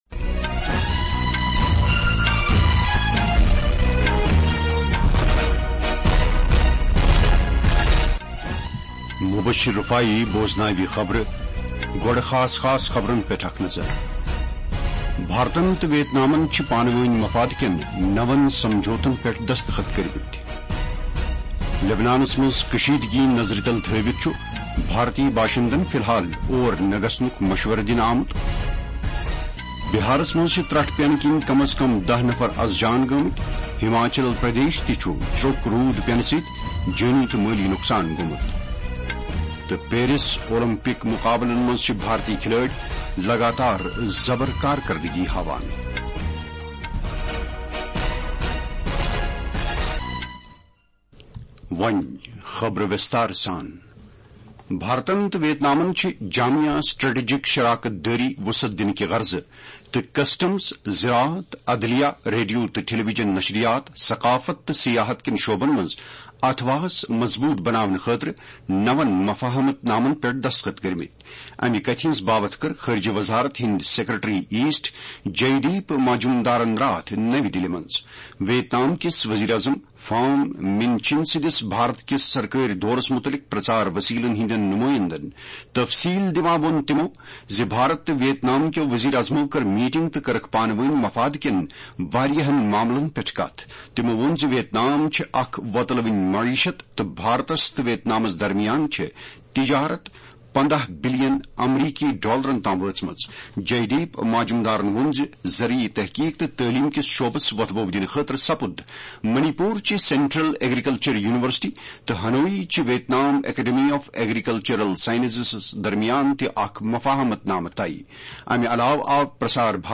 KASHMIRI-NSD-NEWS.mp3